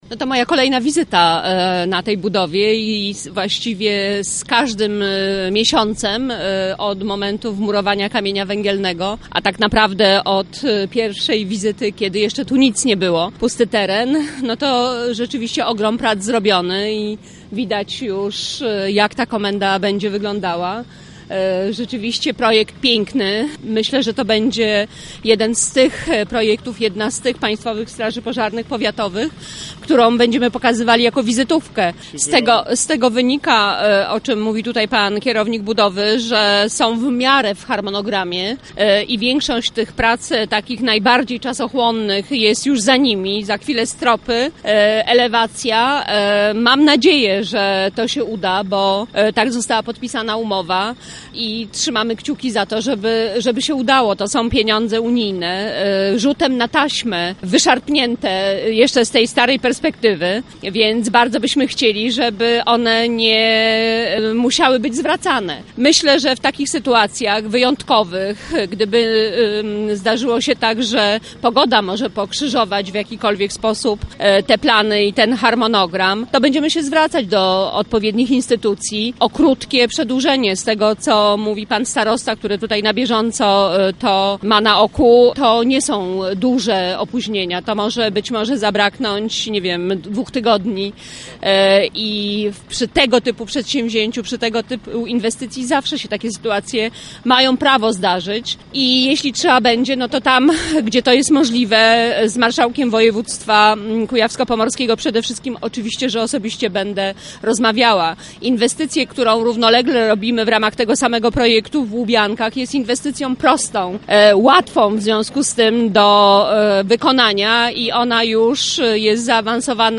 mówiła Minister Teresa Piotrowska.